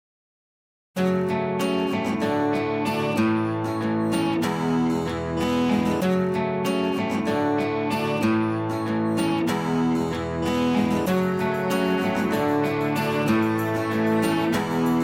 Corporate, Instrumental